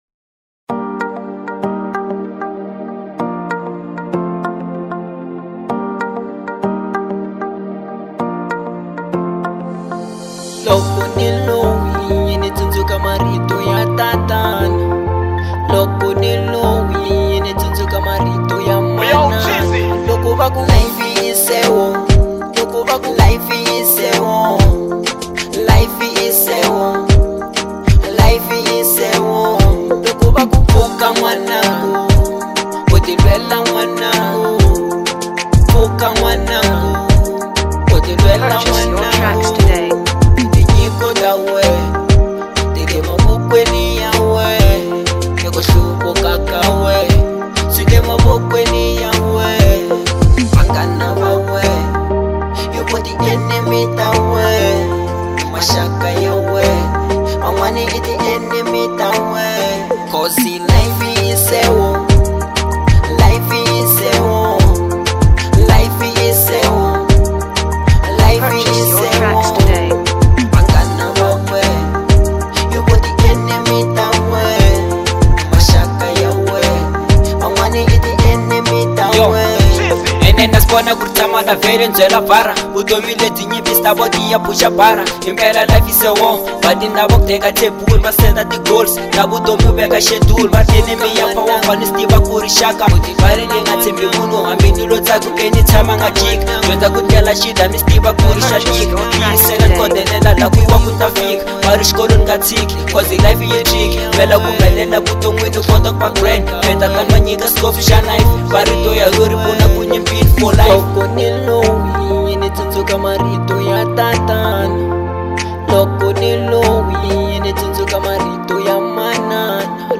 02:55 Genre : Afro Pop Size